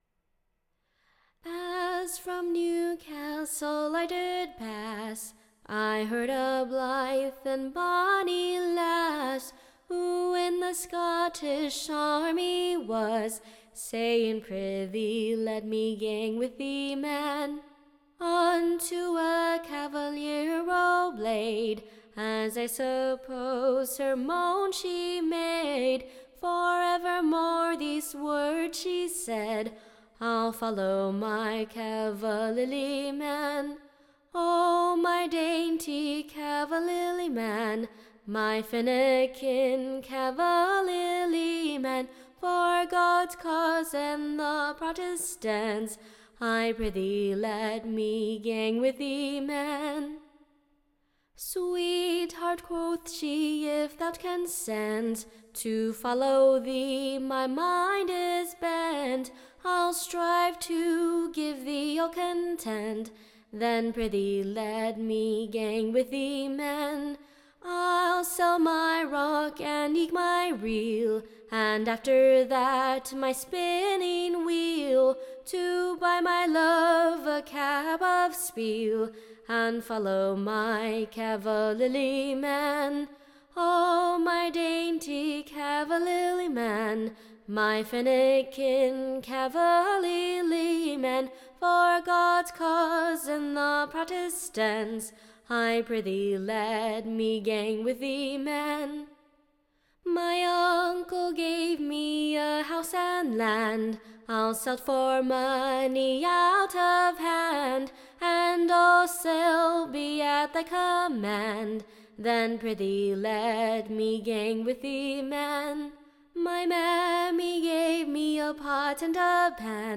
Tune Imprint To a pleasant new Northern Tune.